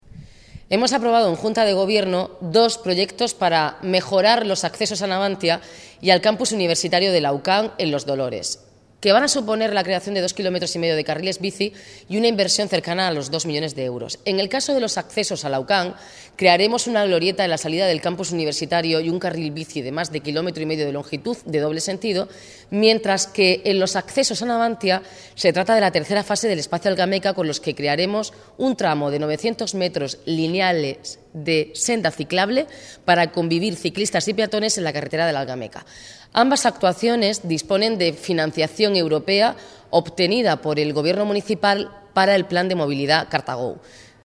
Multimedia Declaraciones de la alcaldesa, Noelia Arroyo, sobre las obras de CartaGO (MP3 - 446,04 KB) El gobierno aprueba las obras del CartaGO que suman m�s de 2,5 km de nuevos carriles bici Galer�a de im�genes